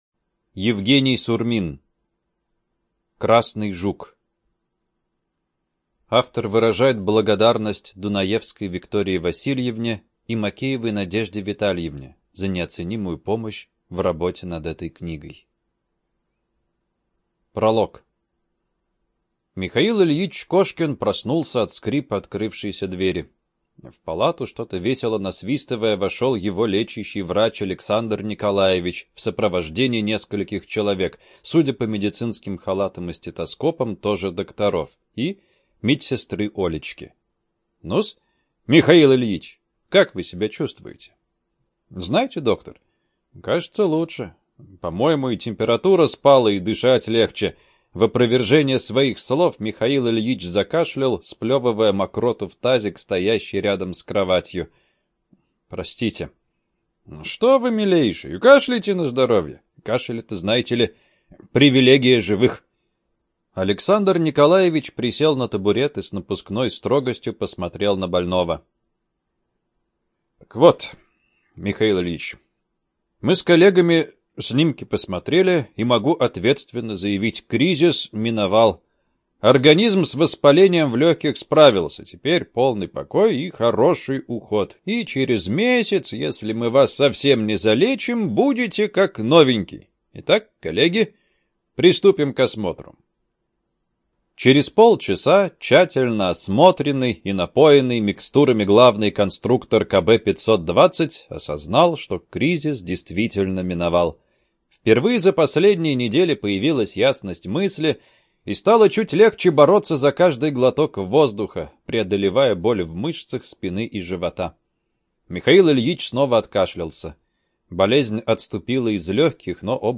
ЖанрФантастика, Альтернативная история